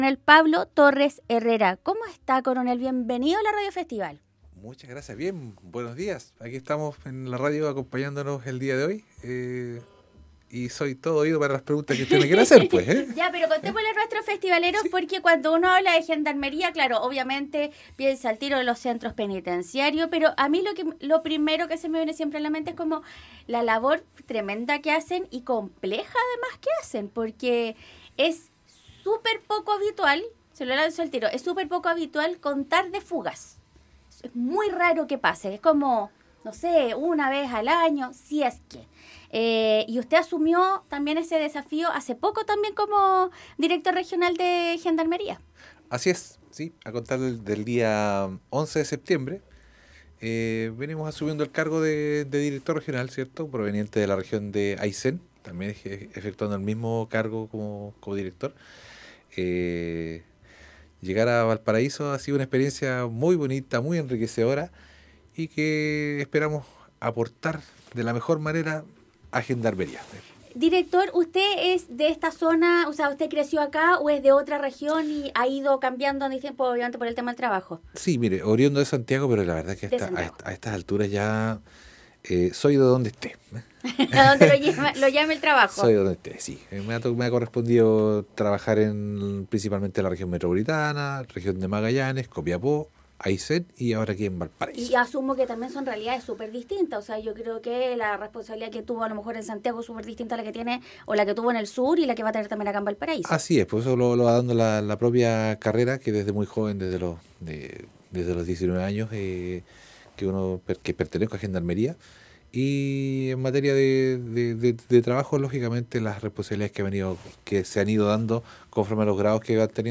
El nuevo Director Regional de Gendarmería Coronel Pablo Torres Herrera estuvo en los estudios de Radio Festival para abordar los distintos talleres que tienen los internos, así como la rehabilitación como una de las principales herramientas.